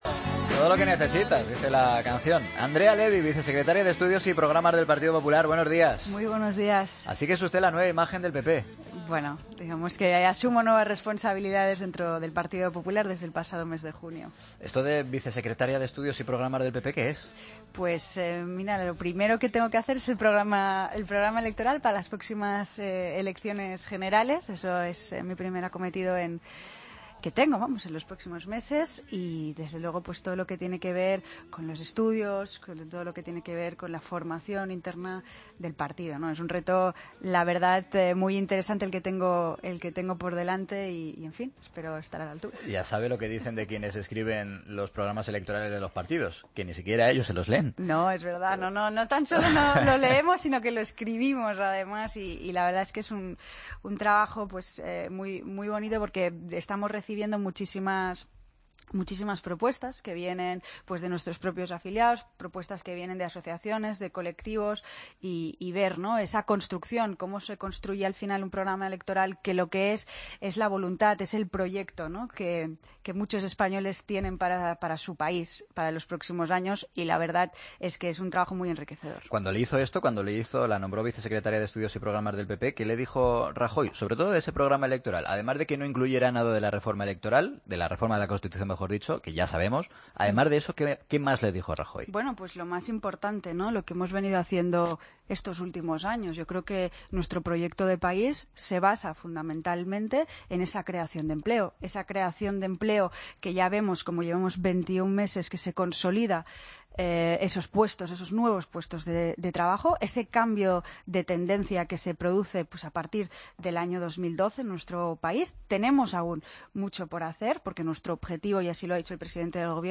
Escucha la entrevista a Andrea Levy en La Mañana
AUDIO: La vicesecretaria de Estudios y Programas del PP ha pasado por los micrófonos de la Cadena COPE este miércoles.